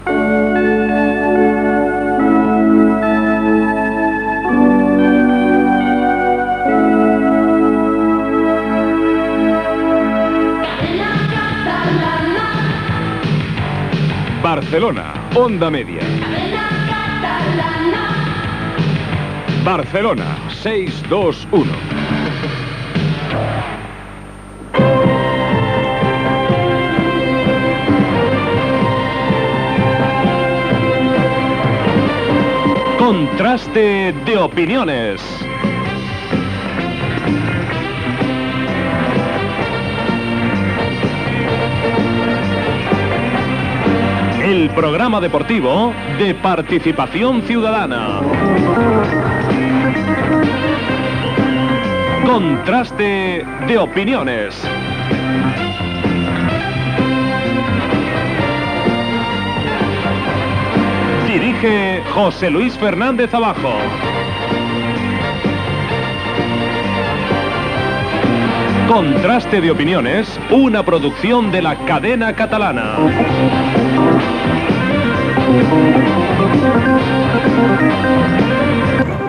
Indicatiu de l'emissora -en aquell moment Cadena Catalana OM- careta del programa.
Esportiu